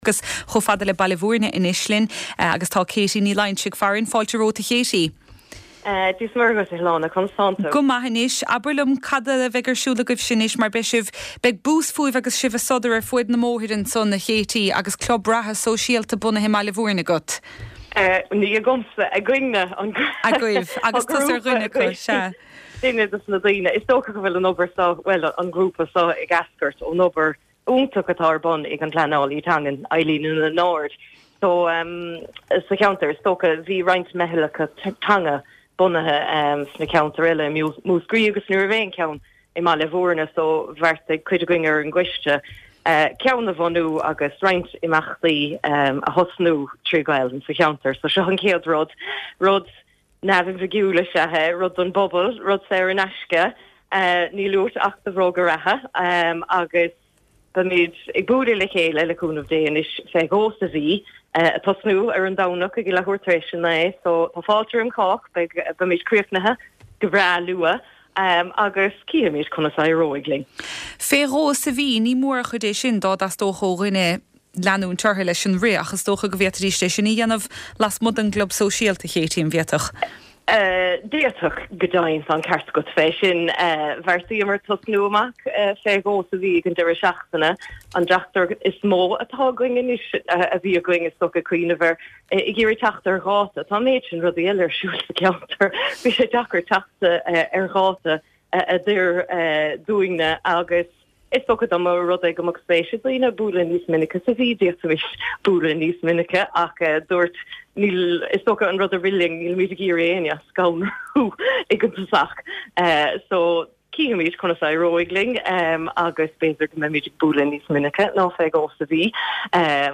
Clár irise an deiscirt. Cuirtear béim san irischlár seo ar scéalta phobail Ghaeltachta na Mumhan i gCiarraí, i gCorcaigh agus i bPort Láirge.